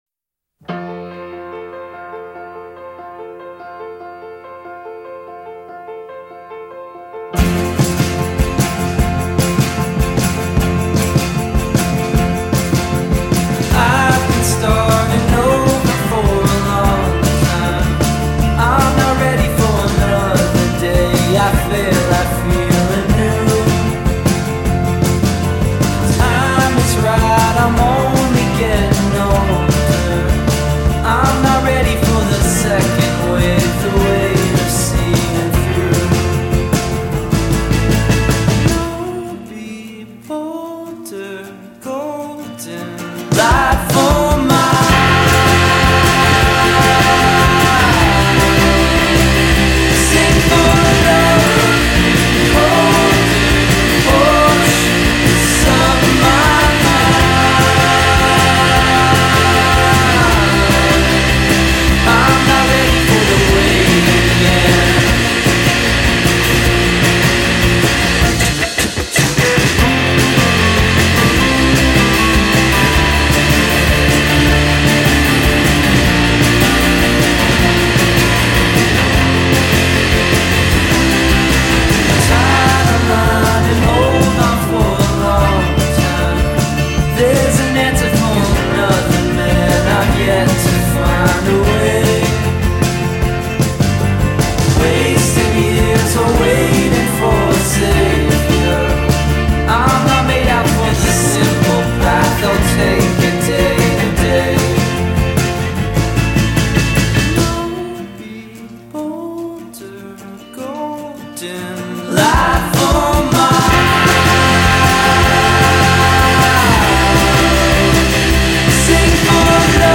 grungige gitarren
lieblichem gesang